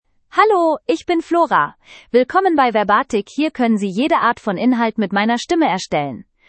Flora — Female German (Germany) AI Voice | TTS, Voice Cloning & Video | Verbatik AI
Flora is a female AI voice for German (Germany).
Voice sample
Female
Flora delivers clear pronunciation with authentic Germany German intonation, making your content sound professionally produced.